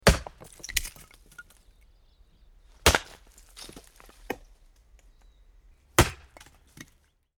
Звуки дров